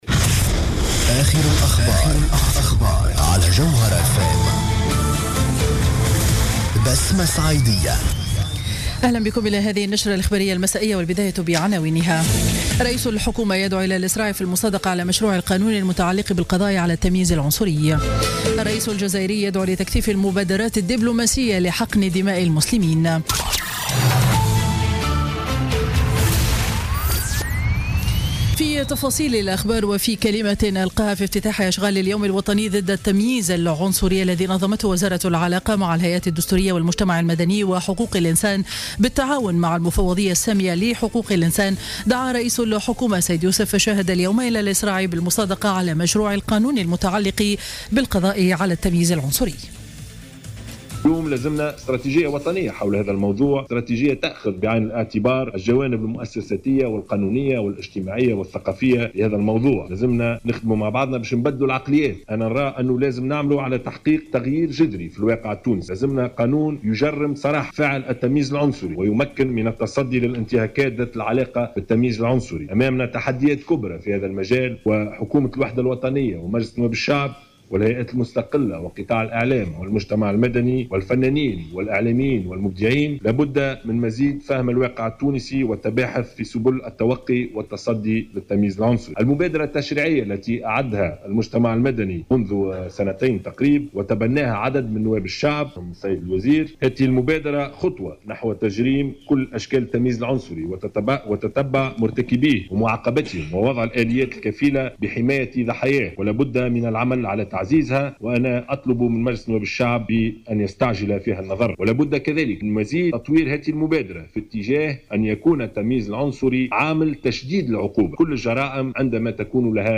نشرة أخبار السابعة مساء ليوم الاثنين 26 ديسمبر 2016